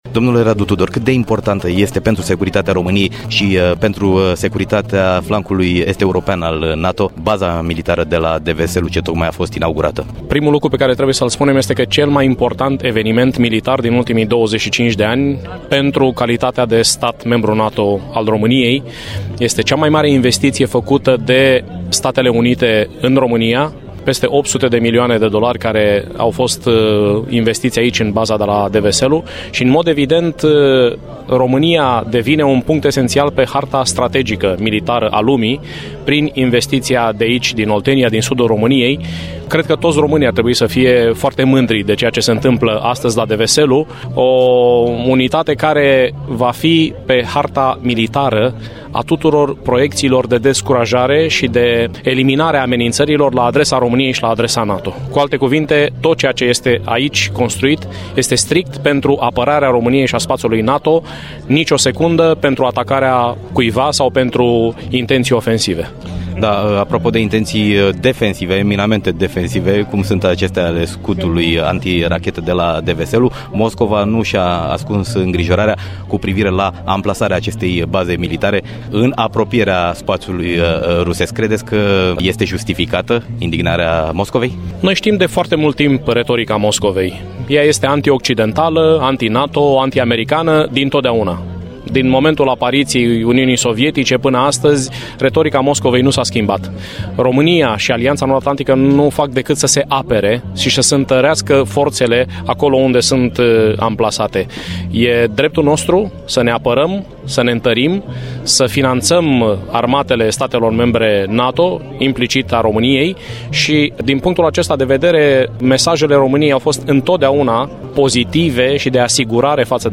Interviu cu Radu Tudor